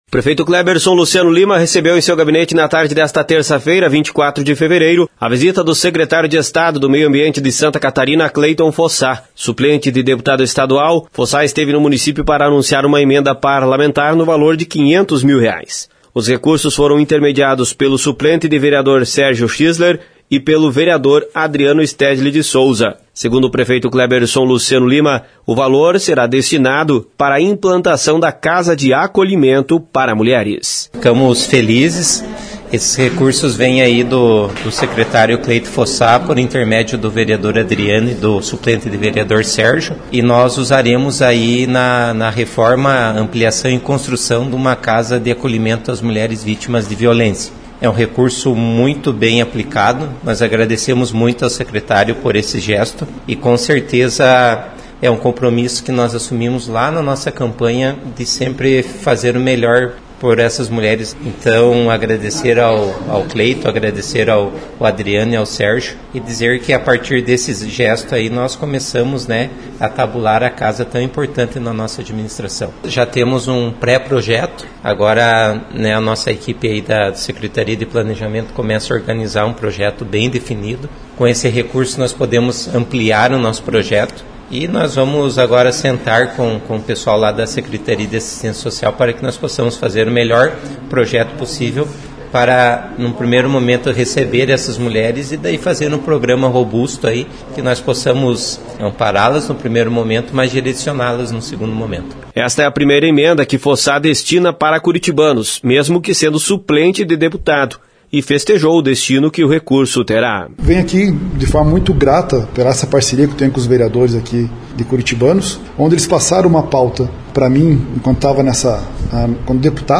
Informações com o repórter